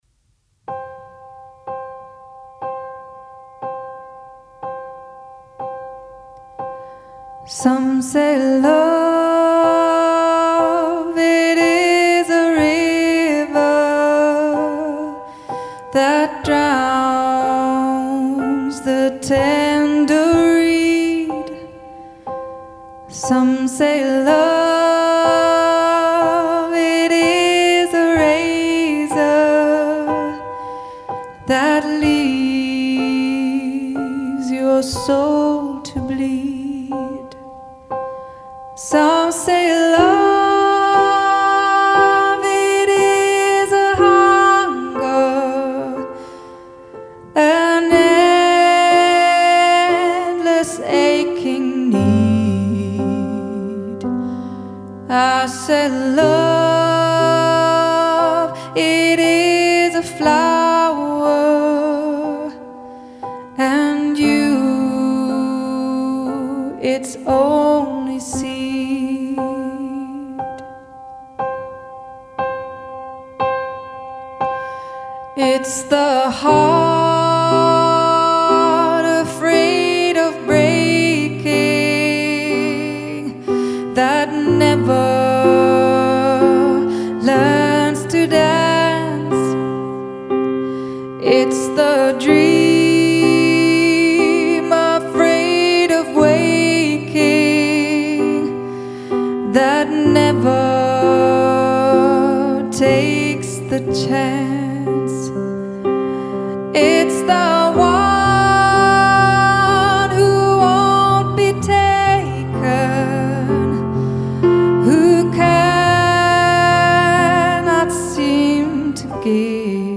Livemusik als Highlight eurer Hochzeit
• Breit gefächertes Repertoire, Jazz & Pop